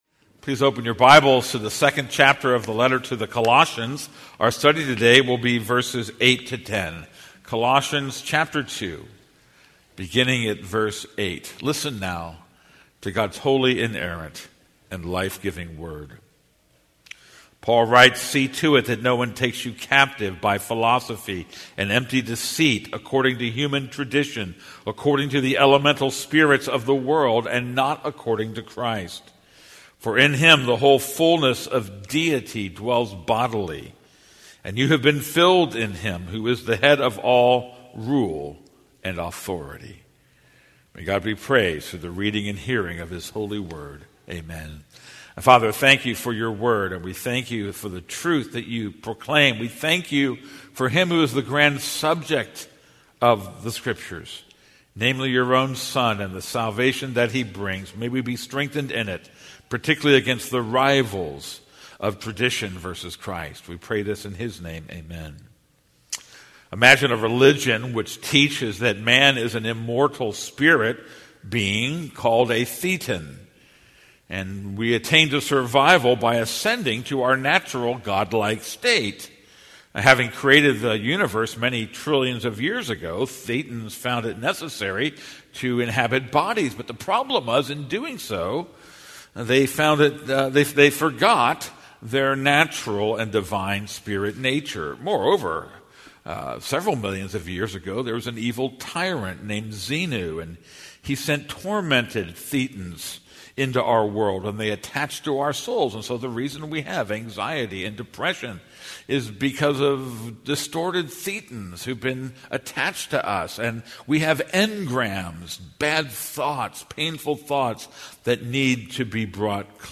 This is a sermon on Colossians 2:8-10.